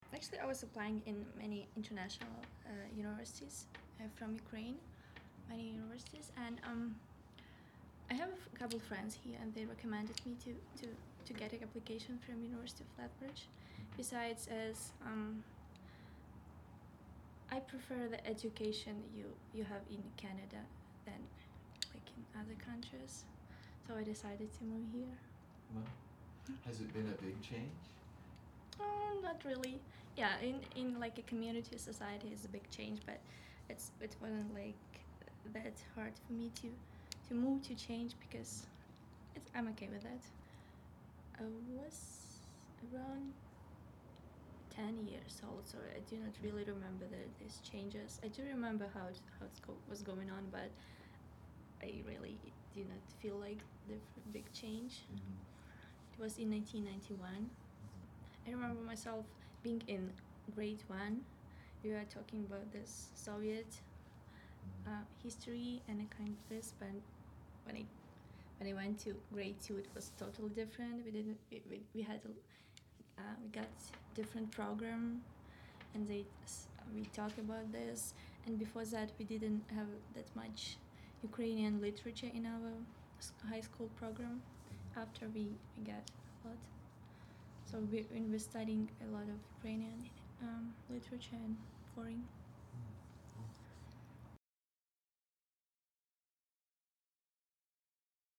A female university student in her early twenties.
ukraine_conversation.mp3